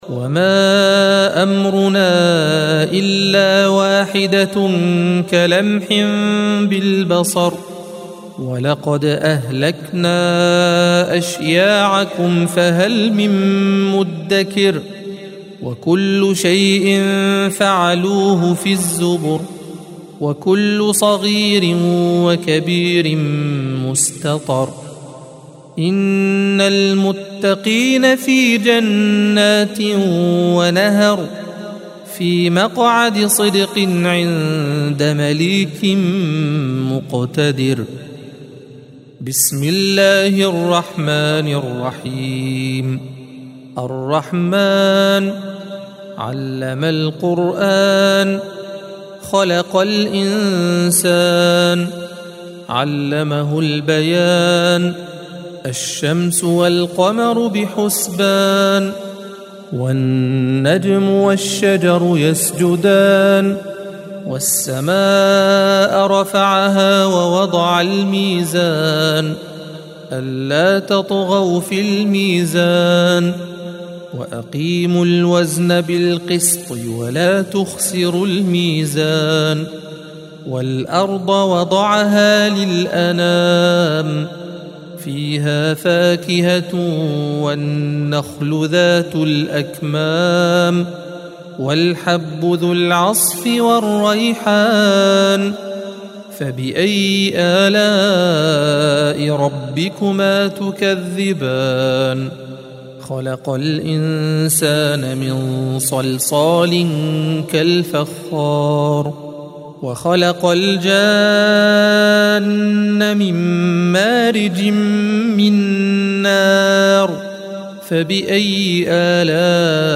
الصفحة 531 - القارئ